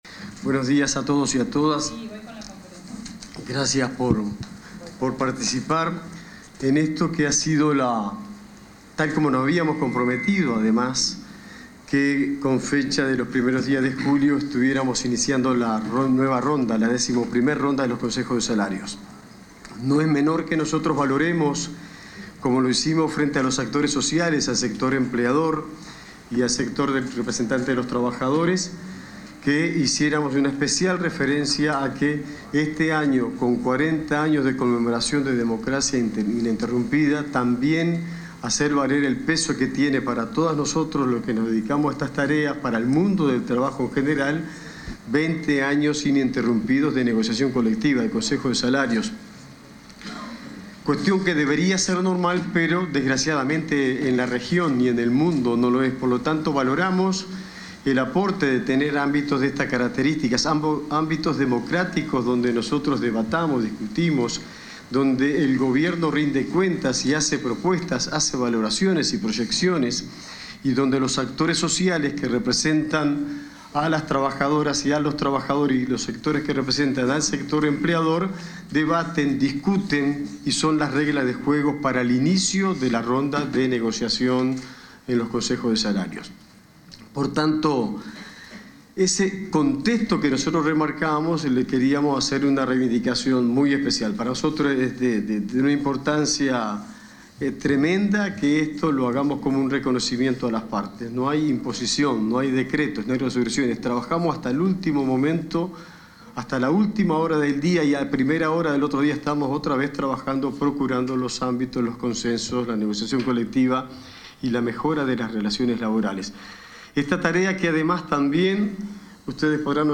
Palabras del ministro de Trabajo y Seguridad Social y del subsecretario de Economía y Finanzas